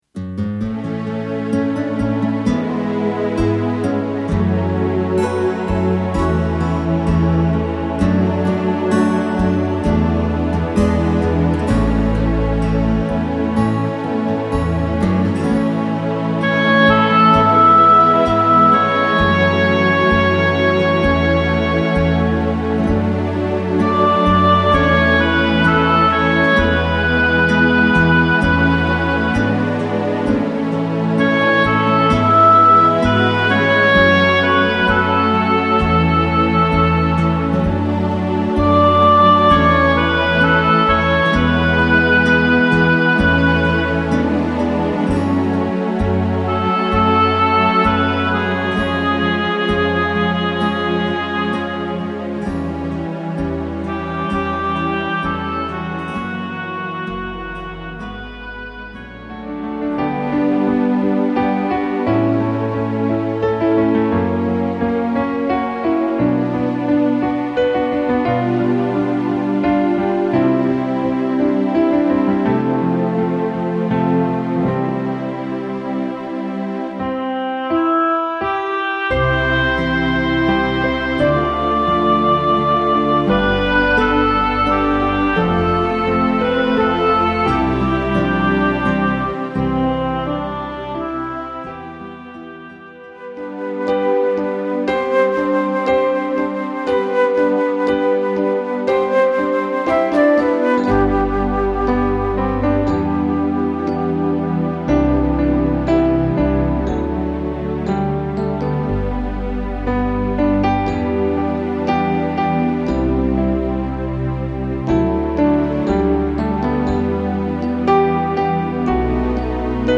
Mélodies populaires pour le réconfort